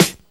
Snares
07_Snare_15_SP.wav